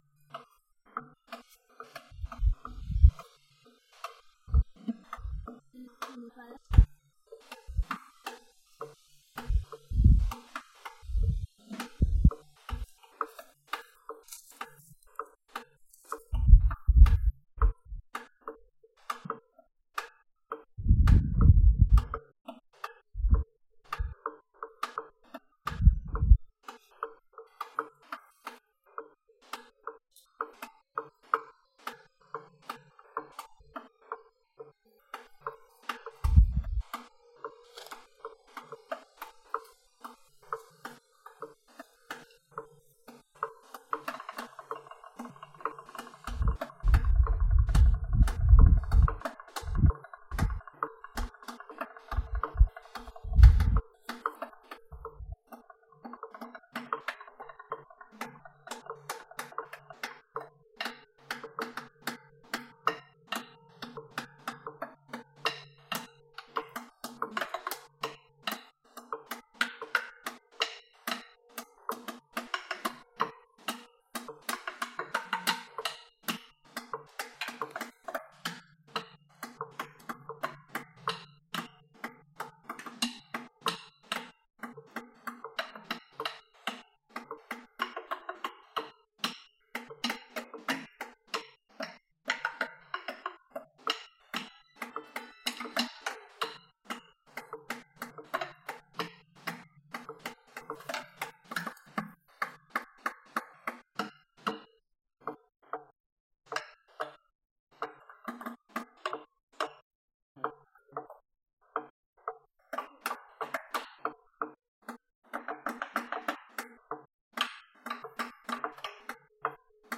A Day of Percussion at the Marimba Tree: Jul 22, 2023: 1pm - 5pm